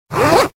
Index of /server/sound/clothing_system/fastener